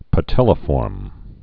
(pə-tĕlə-fôrm)